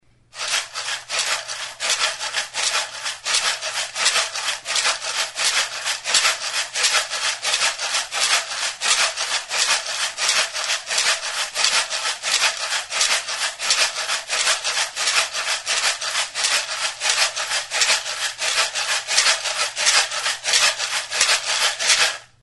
Music instrumentsMaraka; Sonajeroa
Idiophones -> Struck -> Maracas / rattles
Recorded with this music instrument.
Astintzerakoan barruan dituzten hazi aleek hotsa ematen dute.